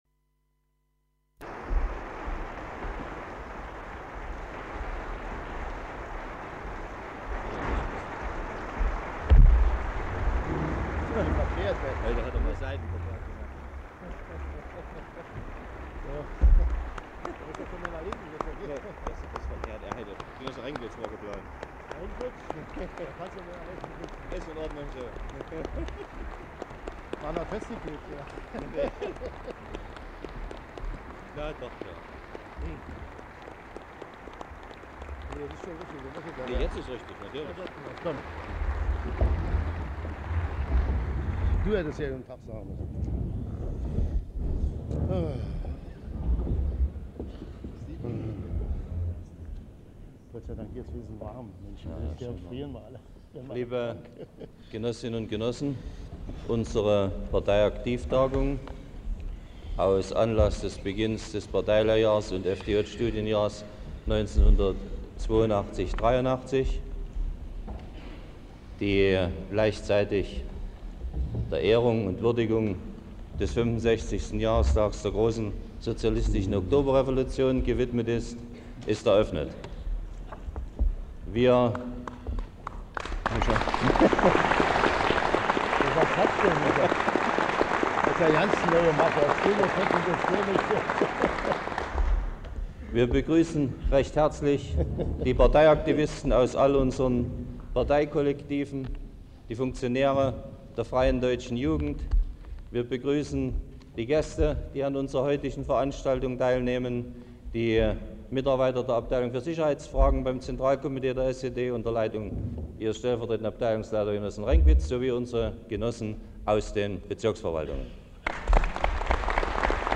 Stasi-Minister Erich Mielke berichtete seinen Mitarbeitern 1982 in einer Rede von der aktuellen Lage. Im ersten Teil kritisierte er unter anderem die Entwicklung einiger kommunistischer Parteien in Europa und betonte die wirtschaftlichen und sozialen Probleme in den kapitalistischen Staaten.
Rede Erich Mielkes auf einer Tagung der SED-Kreisleitung im Ministerium für Staatssicherheit (Teil 1)